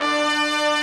BRASS 1-H.wav